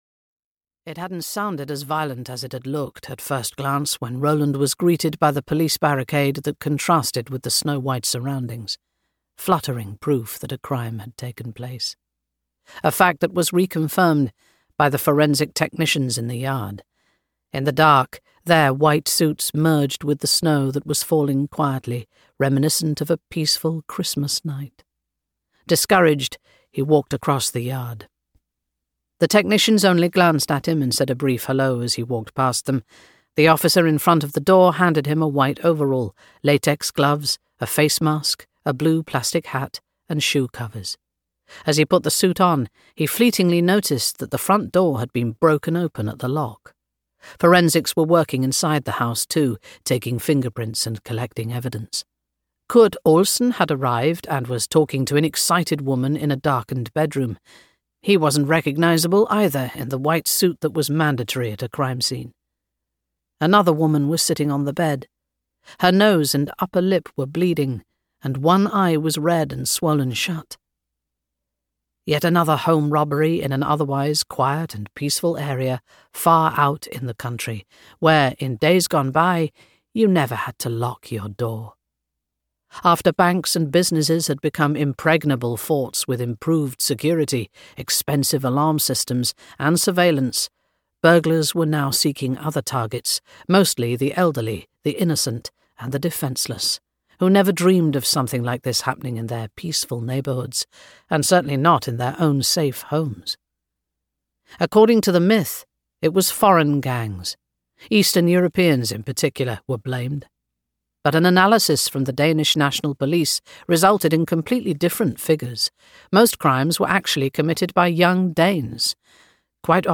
A Strange Intrusion (EN) audiokniha
Ukázka z knihy